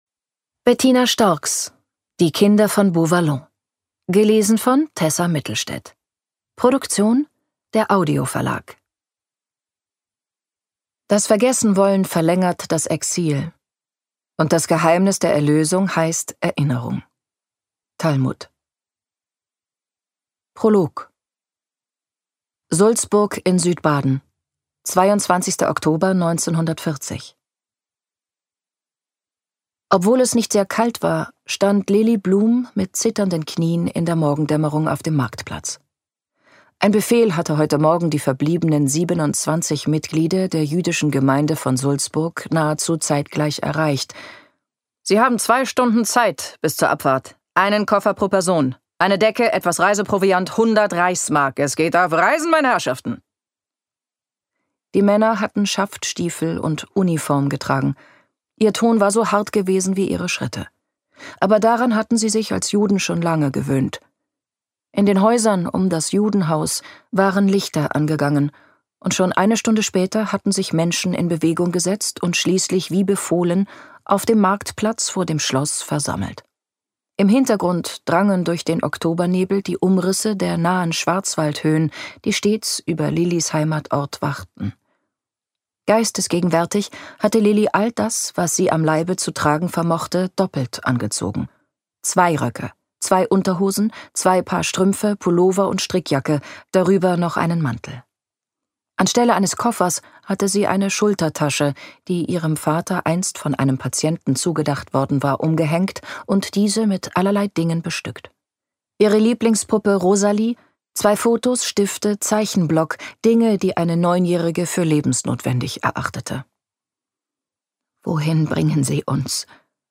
Lesung mit Tessa Mittelstaedt (1 mp3-CD)
Tessa Mittelstaedt (Sprecher)